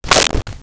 Whip 04
Whip 04.wav